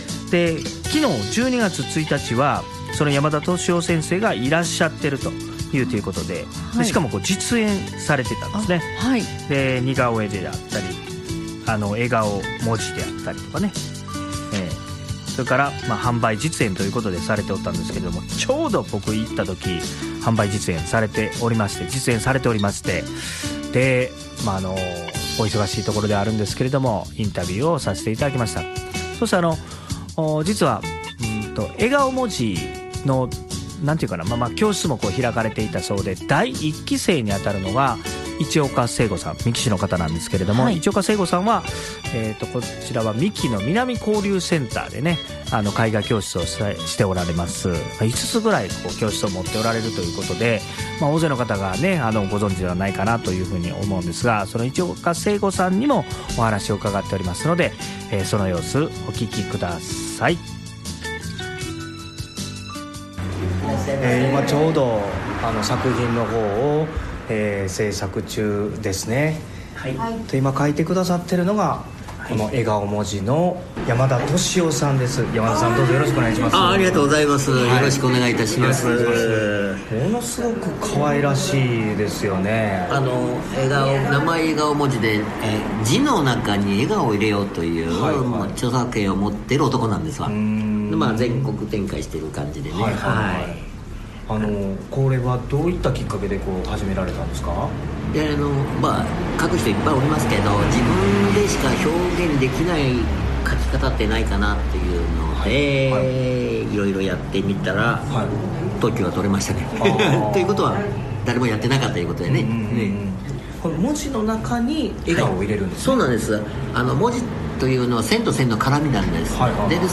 せっかくなので、インタビューをさせていただきました。